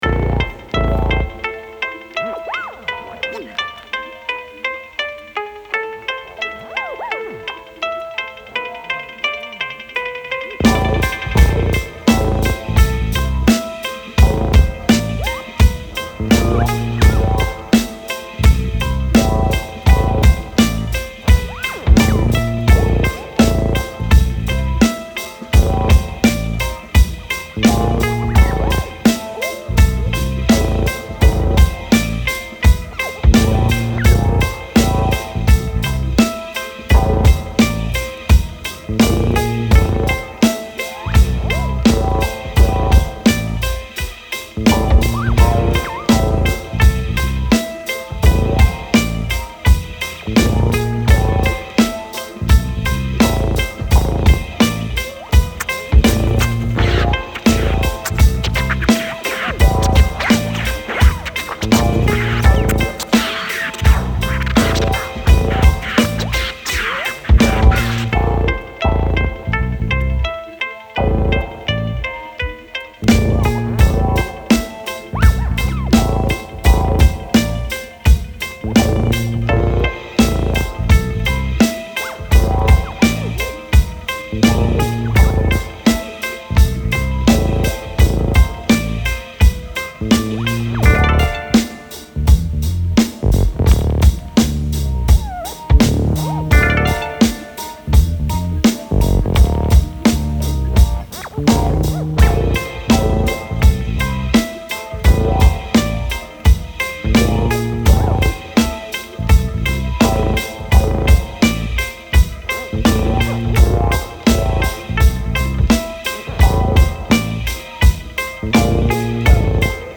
Hip hop beat scratching and tough attitudes on the street.